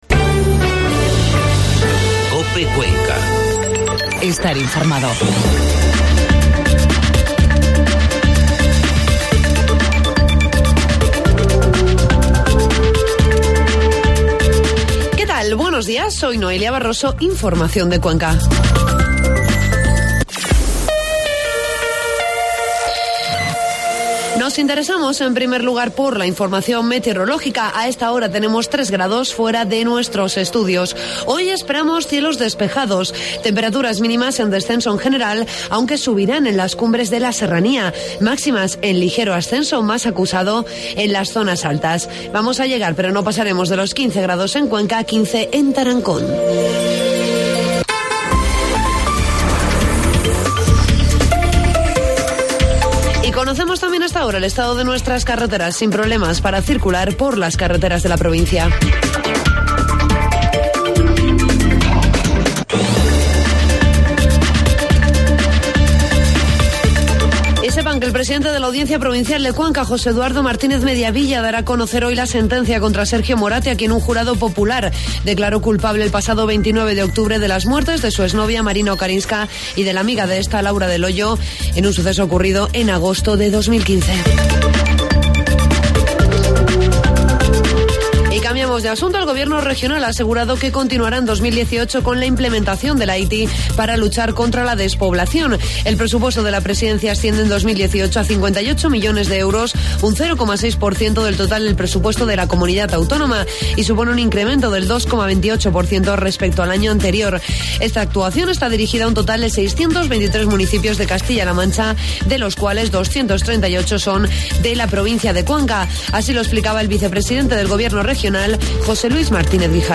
AUDIO: Informativo matinal